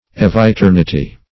Eviternity \Ev`i*ter"ni*ty\, n.